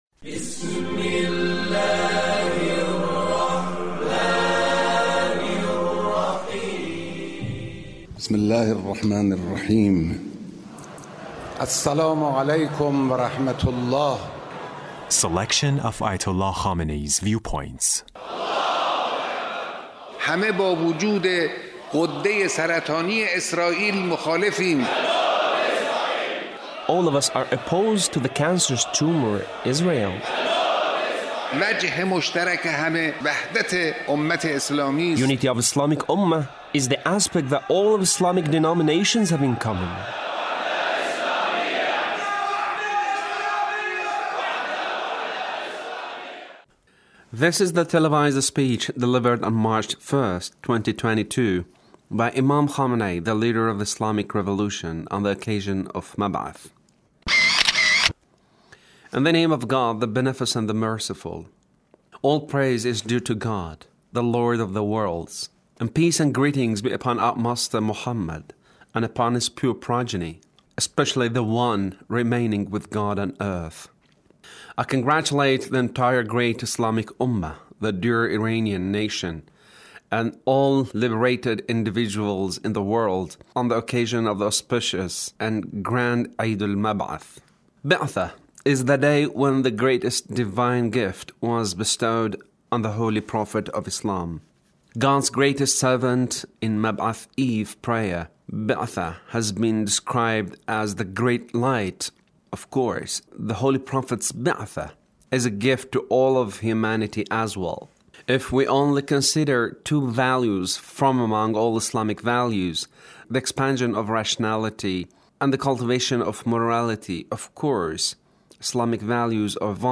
Leader's speech (1337)